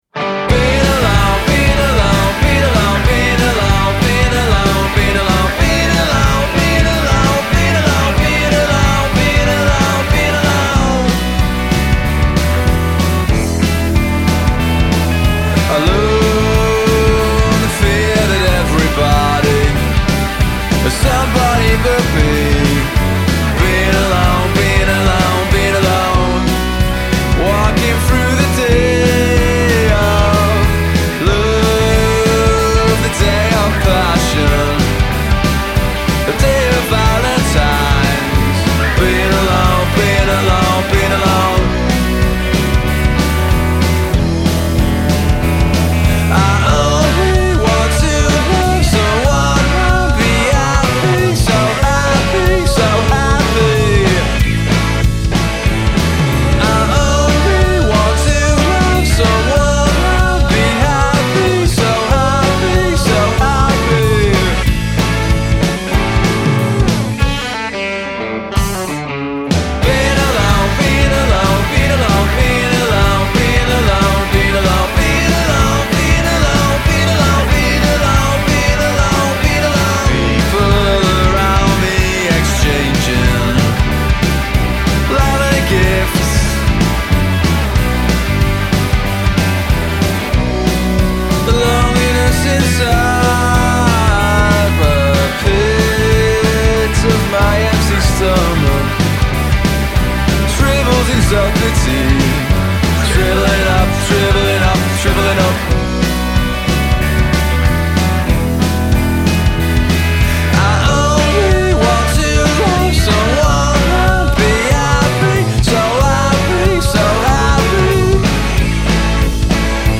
drummer
slick, stomping grooves, they exude wistful overtones